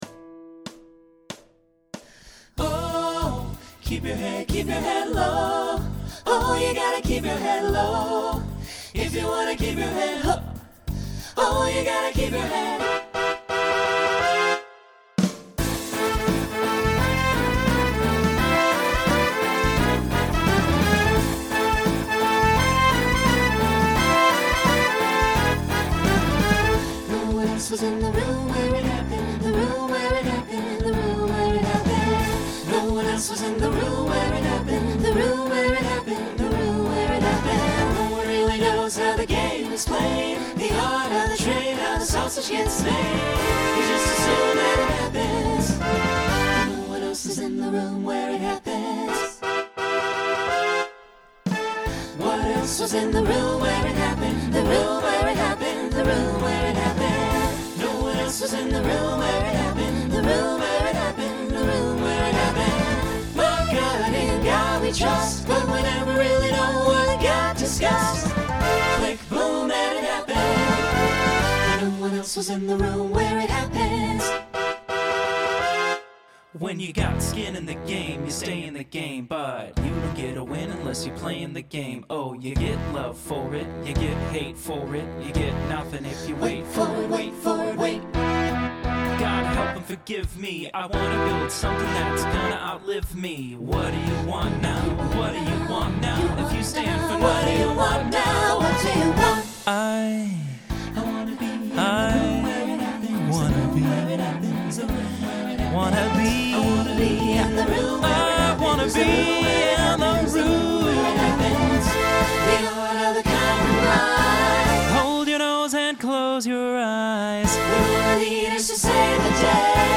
Genre Broadway/Film Instrumental combo
Solo Feature Voicing SATB